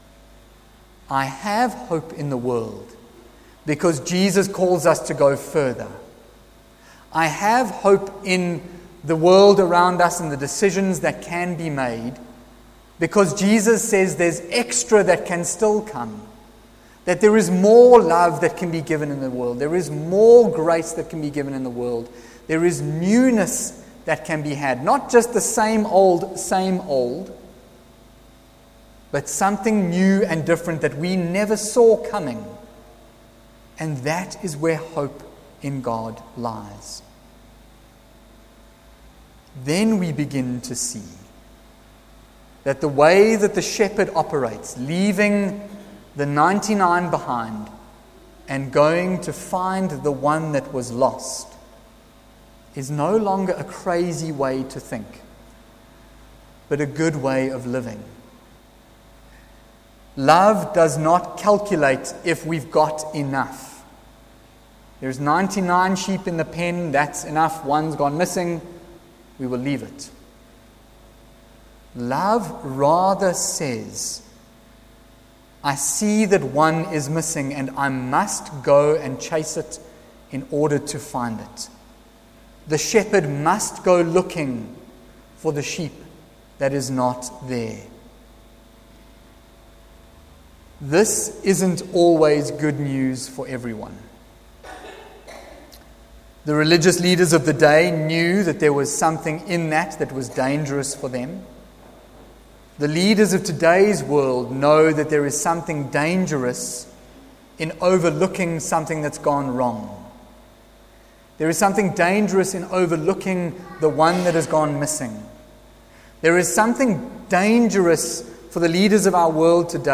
Kopanang Service Part 2 from Trinity Methodist Church, Linden, Johannesburg
Sermons